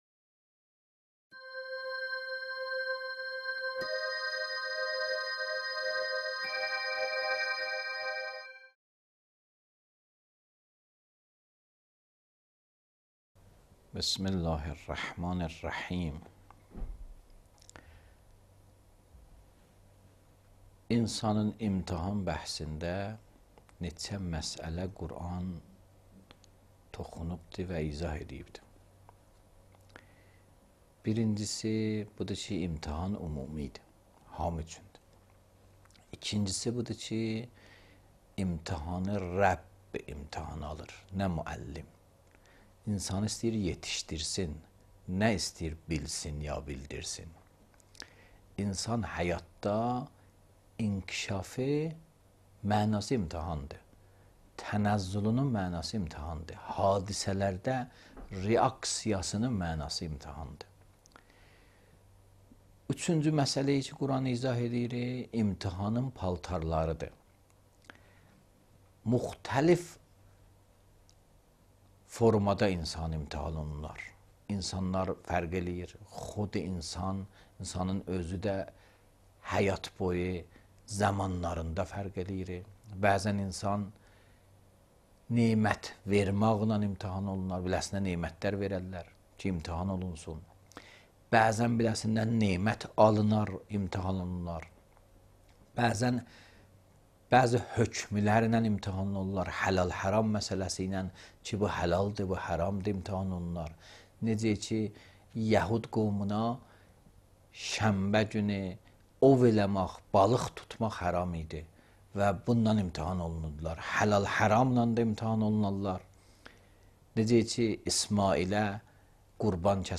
On beşinci dərs